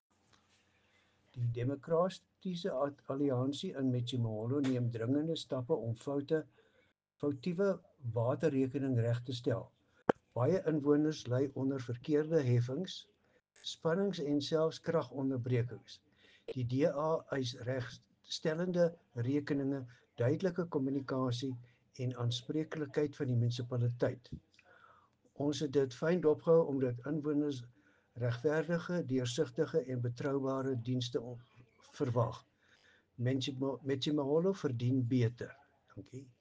Afrikaans soundbites by Cllr Louis van Heerden and Sesotho soundbite by Jafta Mokoena MPL.